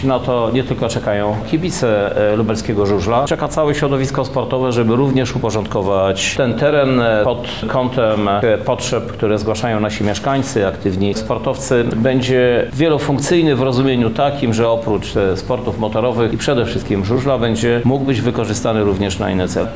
Krzysztof Żuk -mówi Krzysztof Żuk, Prezydent Miasta Lublin